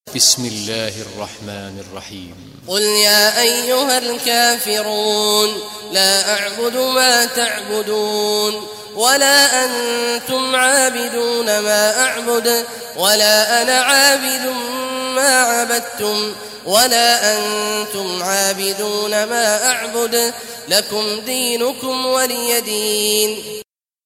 Surah Kafirun Recitation by Sheikh Awad Juhany
Surah Kafirun, listen or play online mp3 tilawat / recitation in the beautiful voice of Imam Sheikh Abdullah Awad al Juhany.